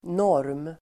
Uttal: [når:m]